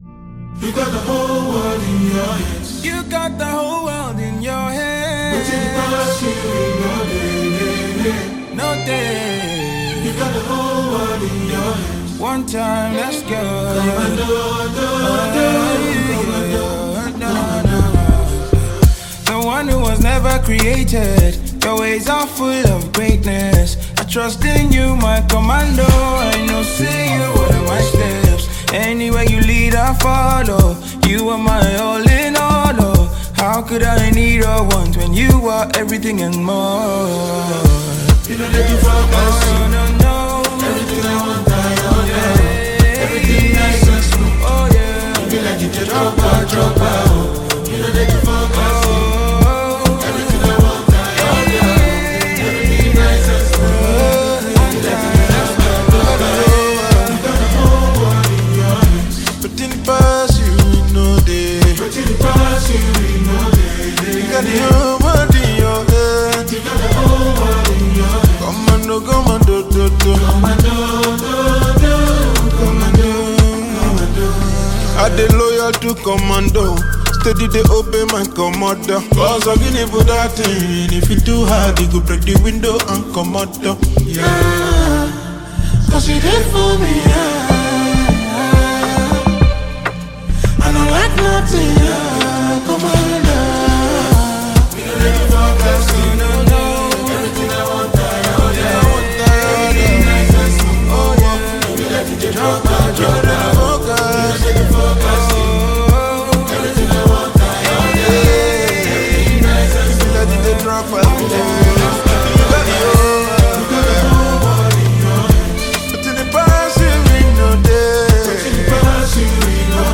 March 12, 2025 Publisher 01 Gospel 0